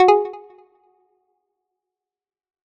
フリー効果音：システム11
プラック系のシンセで決めました！